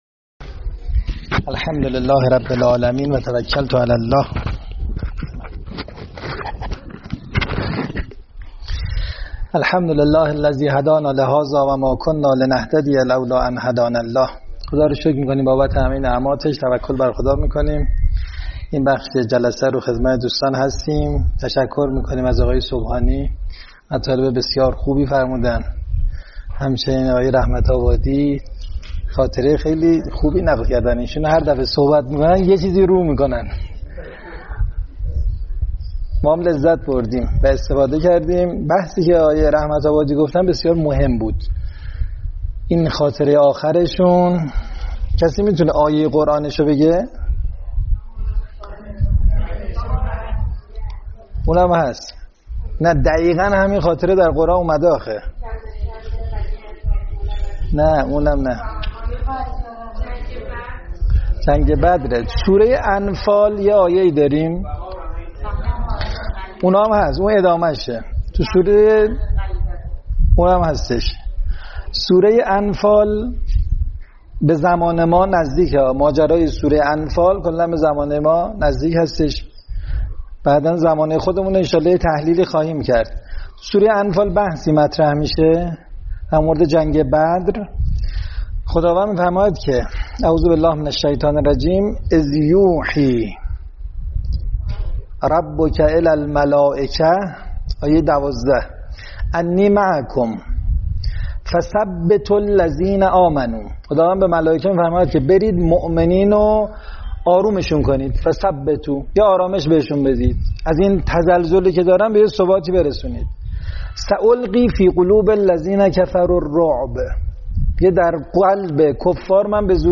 سخنرانی